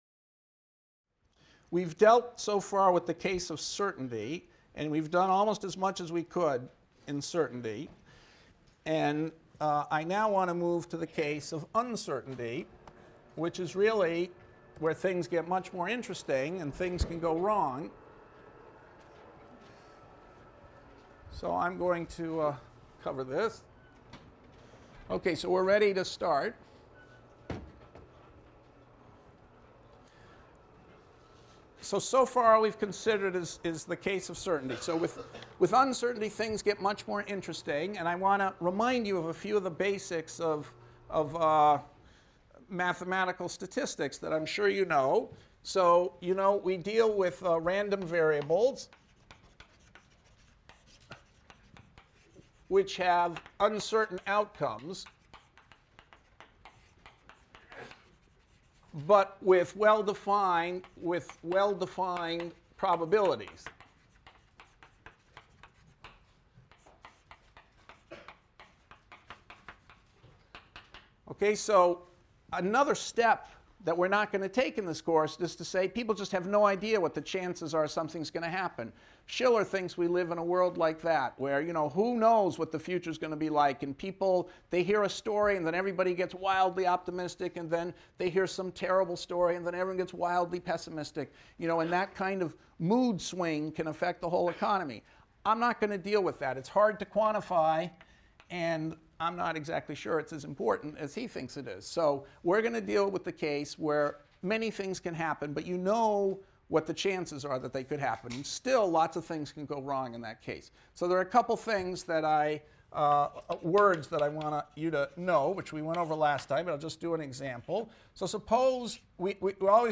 ECON 251 - Lecture 14 - Quantifying Uncertainty and Risk | Open Yale Courses